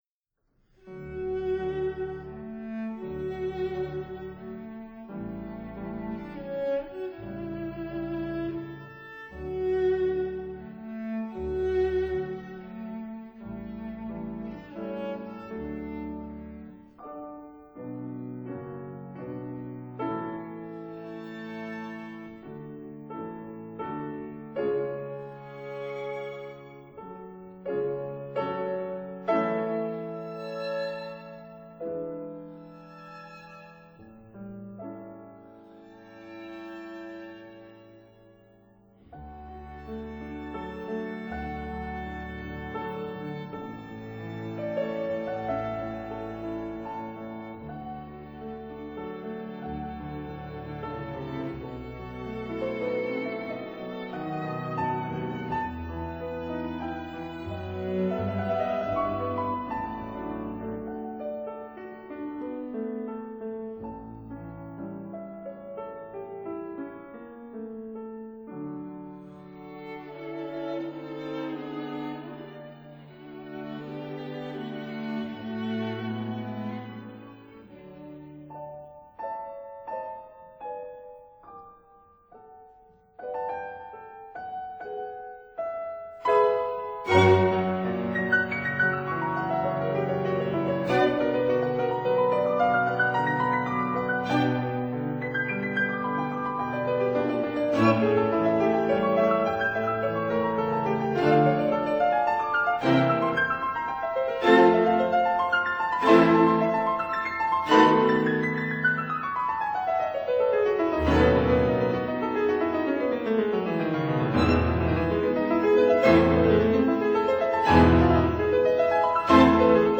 piano
violin
viola
cello
double bass